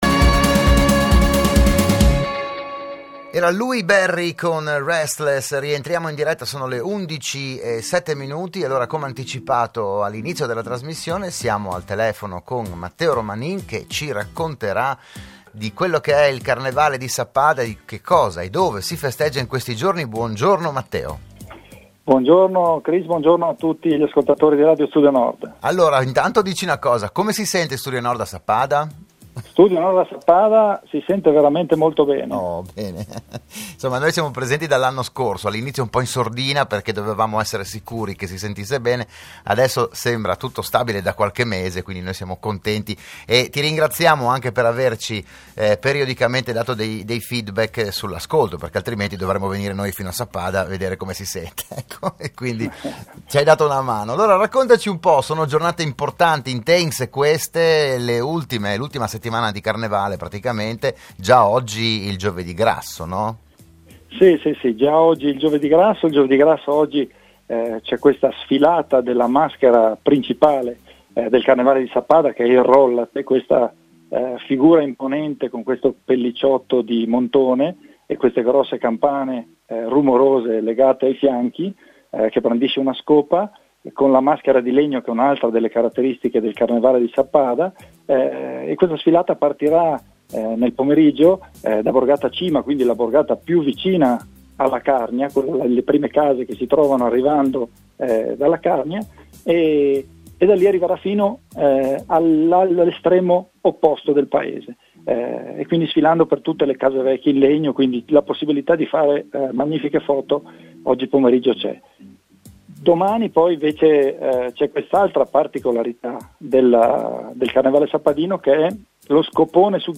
la trasmissione del mattino di Radio Studio Nord